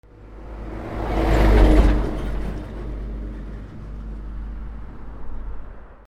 トラック 近距離通過 1
/ E｜乗り物 / E-25 ｜トラック